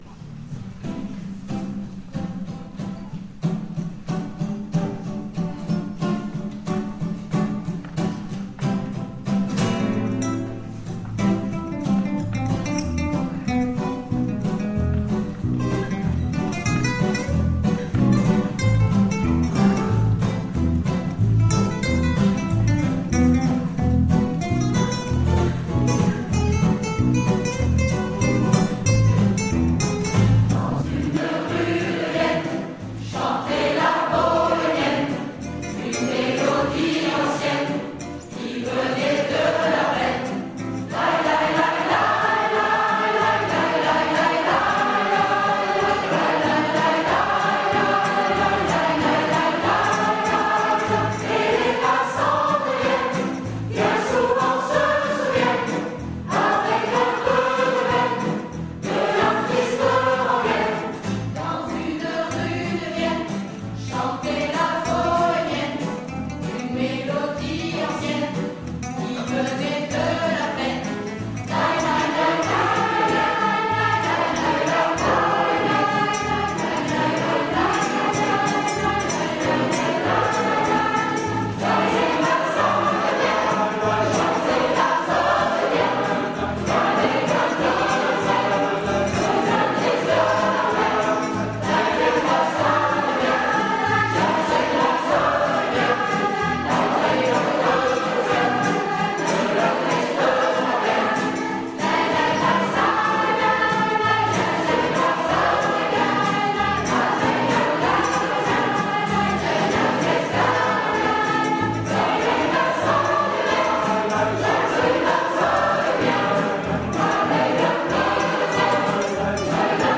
Concert Rétina 2011 – Caseneuve
Les chants communs avec la chorale de jeunes de l’école de musique d’Apt et la chorale d’Apt – Le Tholonet